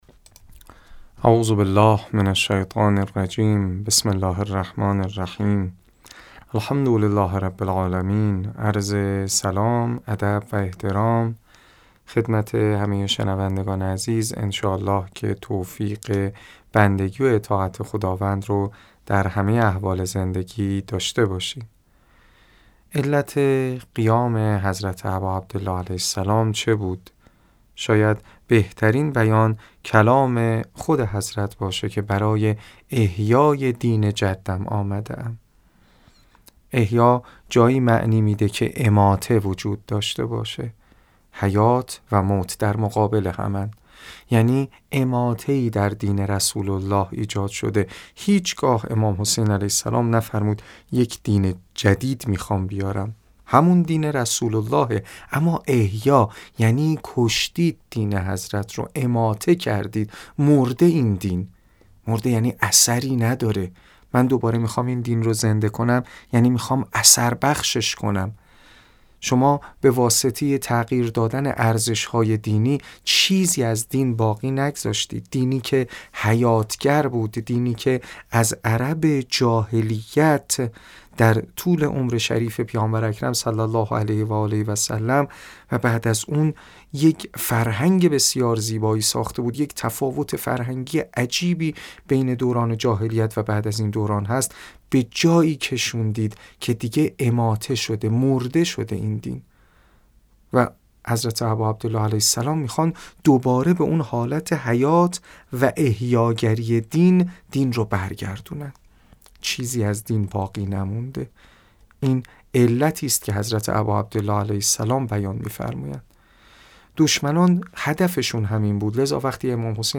در گفت‌و‌گو با خبرنگار فرهنگی خبرگزاری تسنیم